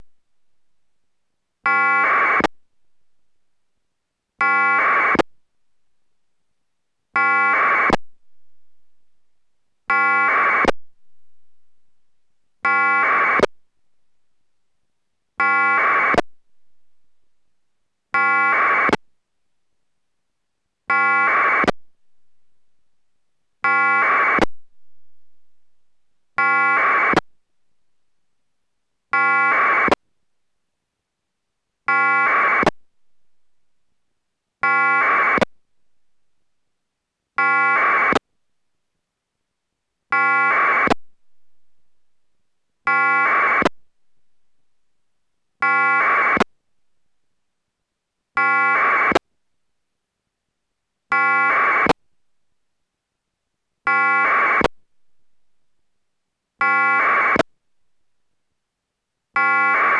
AFSK SRLL 2.0のサンプルサウンドを用意いたしました．
AFSK SRLL 2.0 Sample Sound(32Bytes)
AFSK_SRLL2_SampleSound(32Bytes).wav